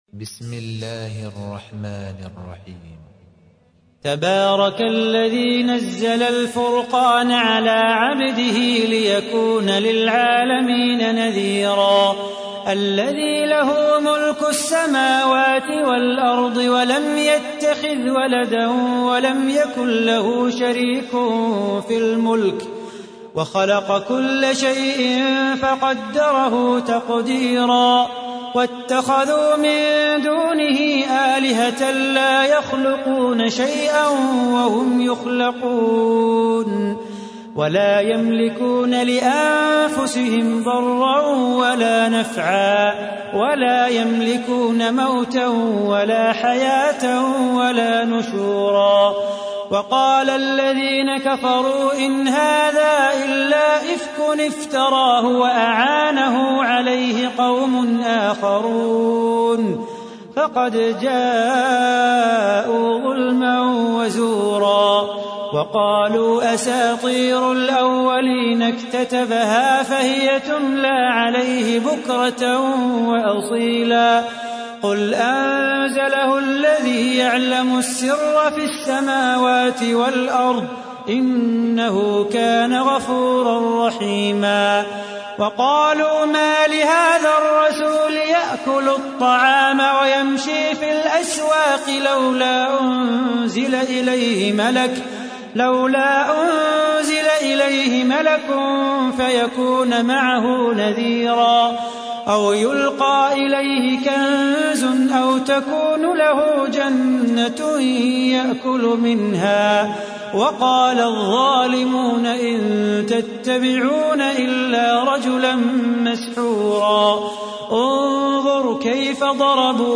تحميل : 25. سورة الفرقان / القارئ صلاح بو خاطر / القرآن الكريم / موقع يا حسين